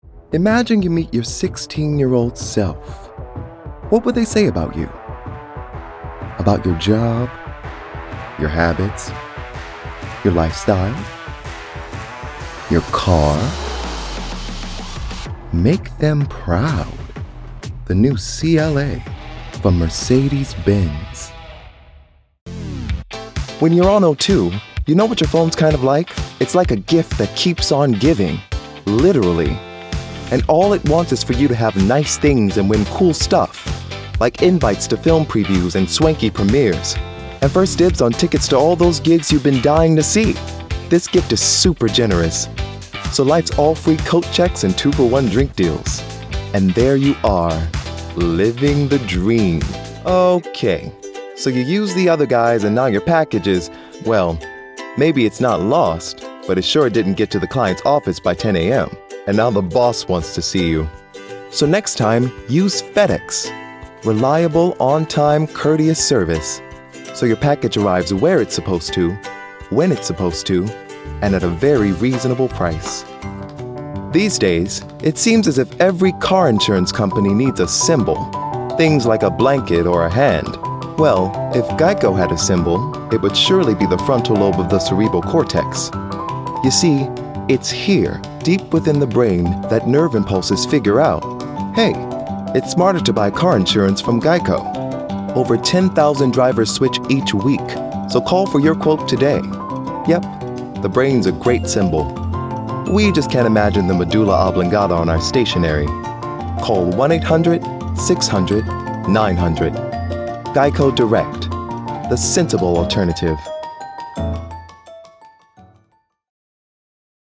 Accent: American Characteristics: Friendly and Warm Age: 20-30 View on spotlight Commercial
Commercial-VO-Demo-copy.mp3